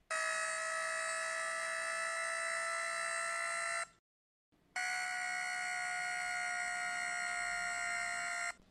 Звуки бинокля
Механический звук зума при приближении в бинокле